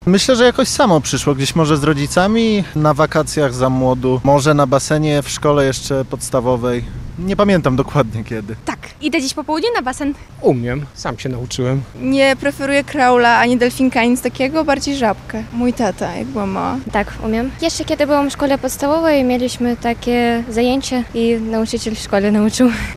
Spytaliśmy na ulicach Lublina, ilu mieszkańców naprawdę umie pływać i czy czują się pewnie w wodzie.